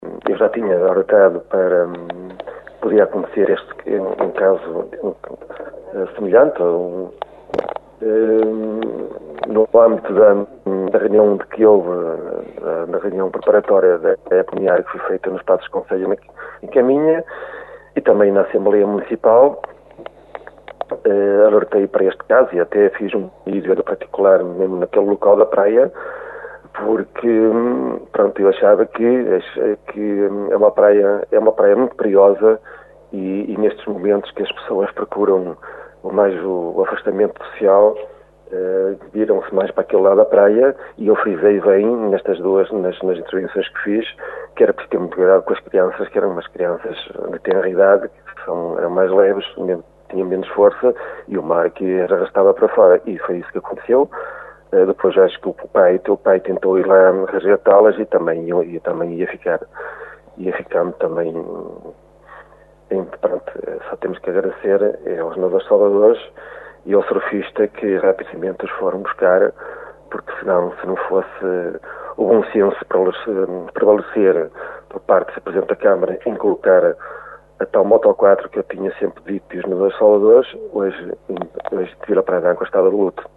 Em declarações à Rádio Caminha, o presidente da Junta explica que já previa que situações destas pudessem acontecer e por isso a sua insistência junto da Câmara para que esta equipa de nadadores salvadores fosse assegurada nas zonas não concessionadas.